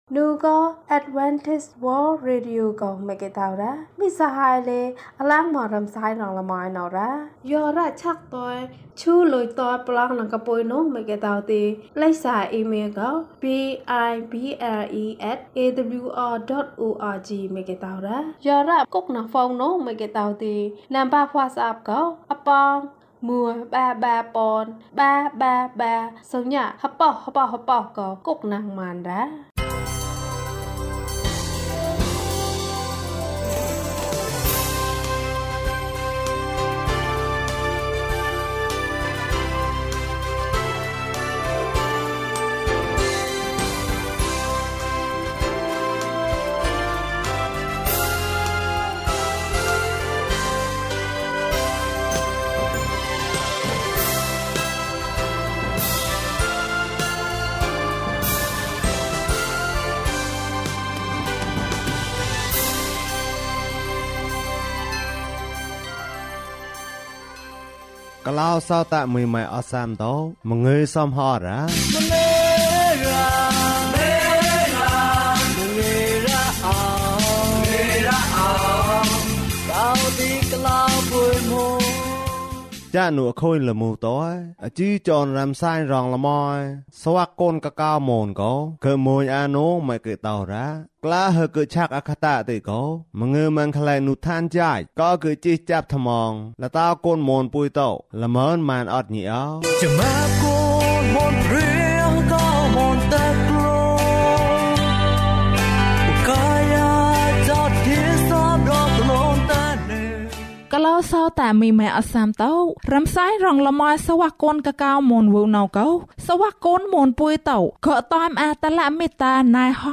မိုးရွာတဲ့နေ့။ ကျန်းမာခြင်းအကြောင်းအရာ။ ဓမ္မသီချင်း။ တရားဒေသနာ။